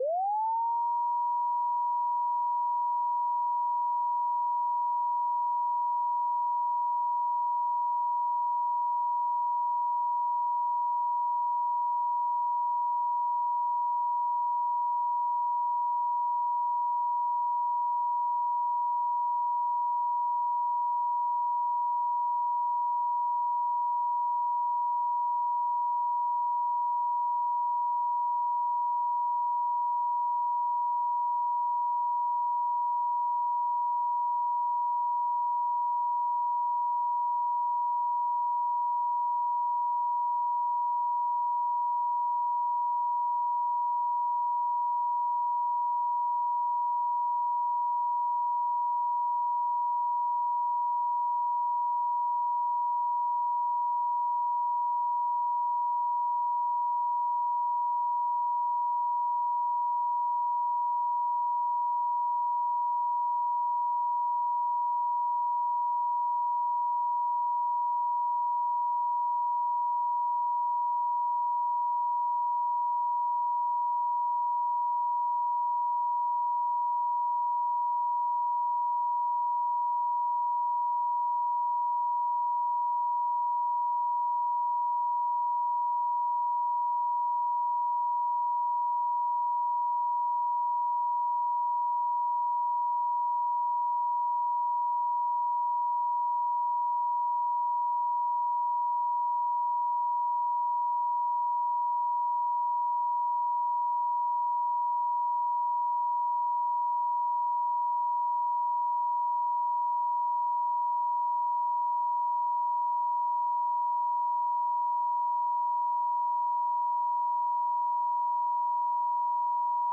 963 Hz Tone Sound Solfeggio Frequency
Solfeggio Frequencies